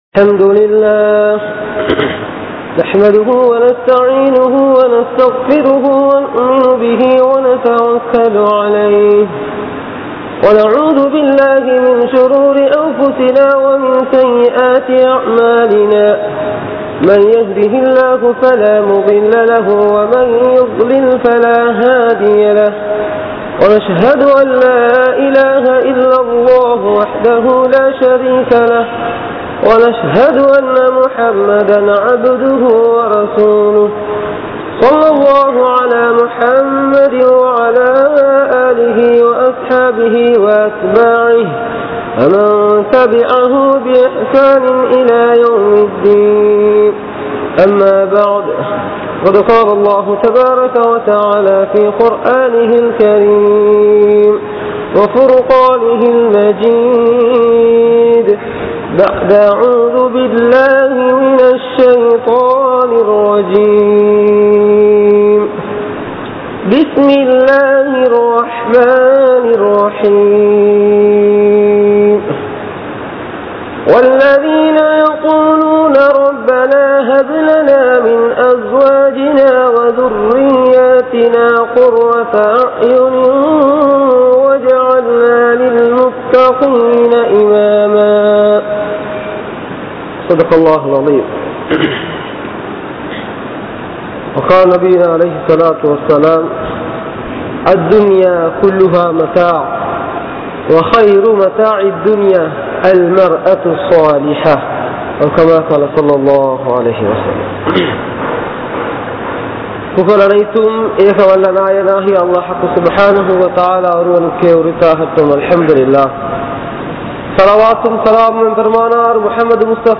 Thirumana Vaalvil Santhoasam Veanduma? (திருமண வாழ்வில் சந்தோசம் வேண்டுமா?) | Audio Bayans | All Ceylon Muslim Youth Community | Addalaichenai